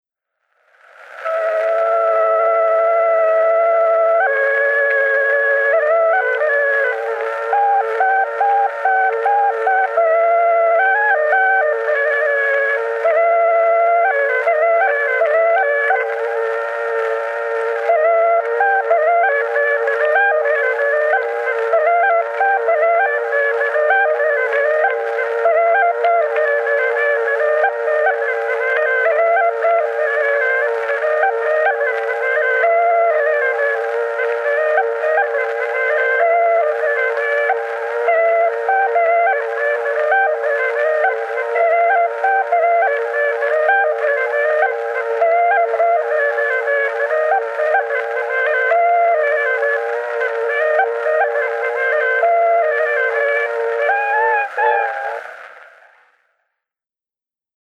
Torupilli Jussi 7. lugu _ Emmaste _ Juhan Maaker _ torupill_folk_noodikogu.mp3